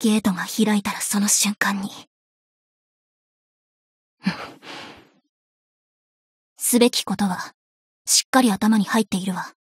贡献 ） 协议：Copyright，其他分类： 分类:爱慕织姬语音 您不可以覆盖此文件。